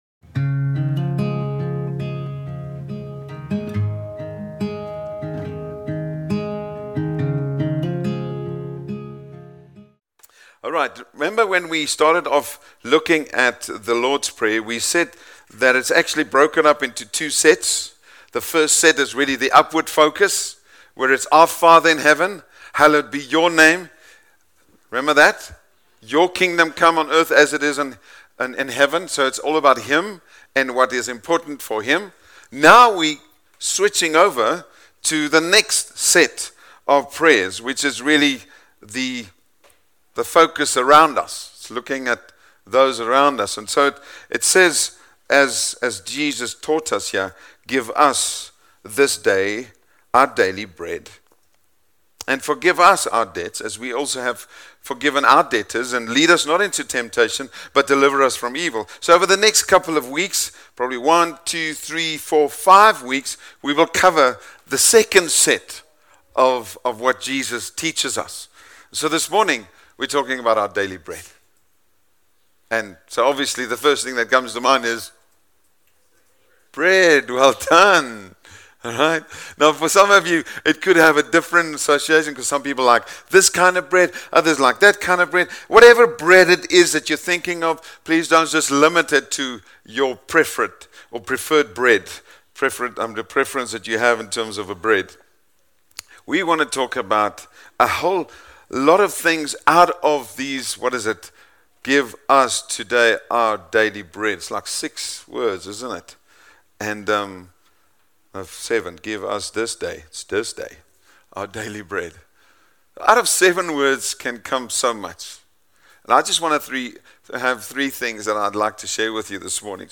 Our-Daily-Bread-Sermon.mp3